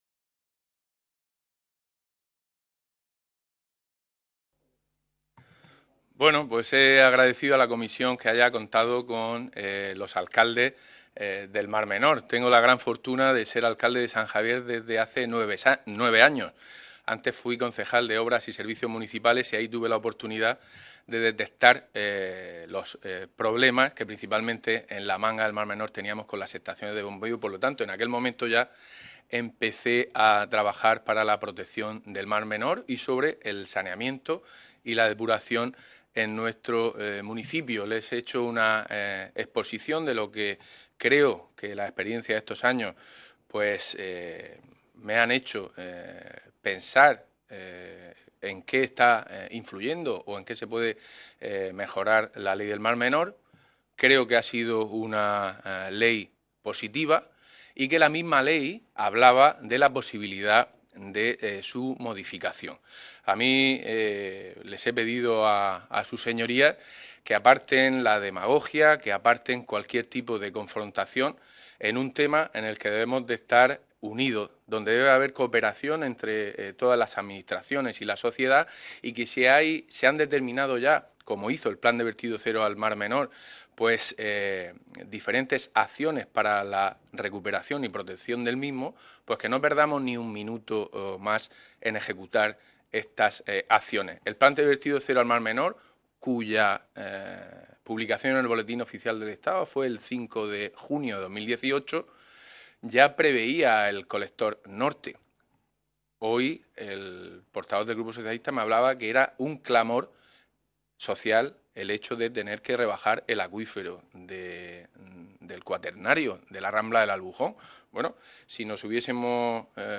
Ruedas de prensa tras la Comisión de Asuntos Generales e Institucionales, de la Unión Europea y Derechos Humanos
• José Miguel Luengo Gallego, alcalde del Ayuntamiento de San Javier